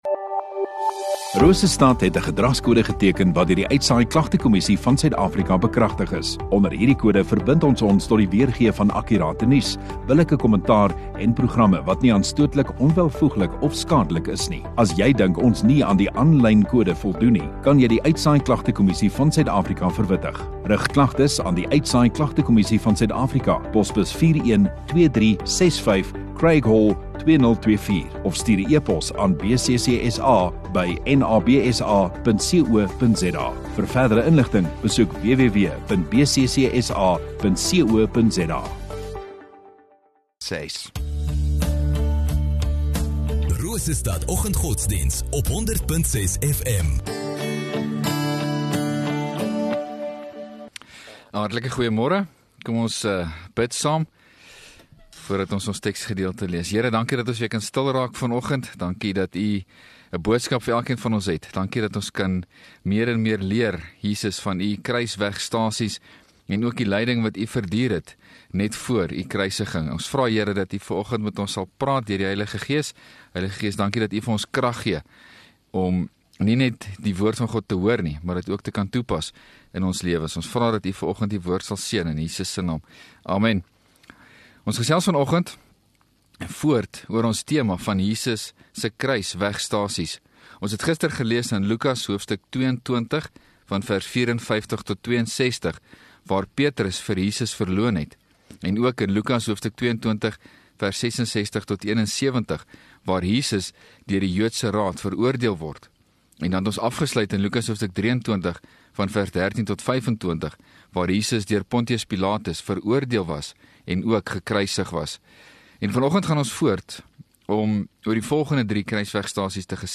5 Mar Donderdag Oggenddiens